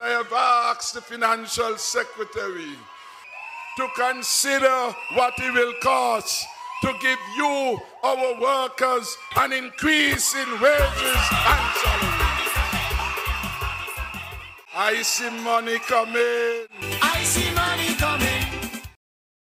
With the chant of “I see money coming” in the background, SKN’s Prime Minister and Leader of the People’s Labour Party (PLP), Dr. Timothy Harris told a raucous crowd of Convention goers on Saturday, the following: